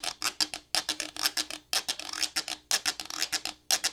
Guiro.wav